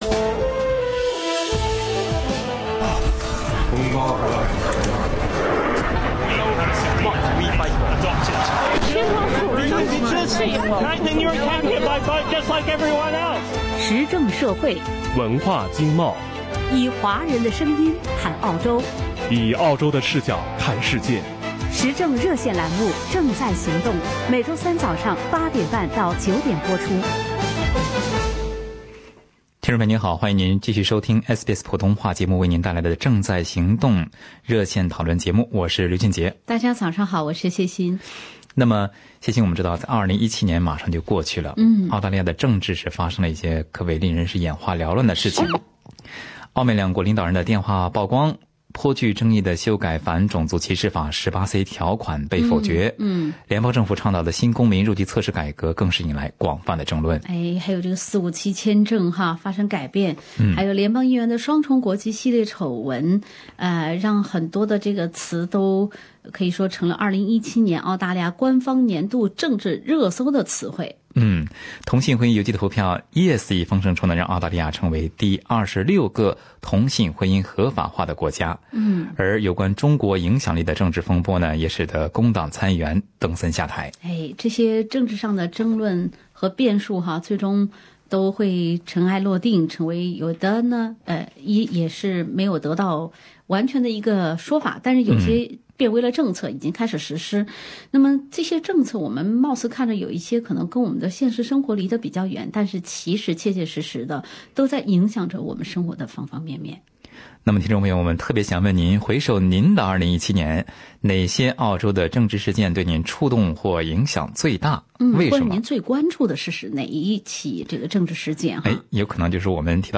本期《正在行动》节目，听众朋友分享了他们心目中感受最深的澳洲政治事件。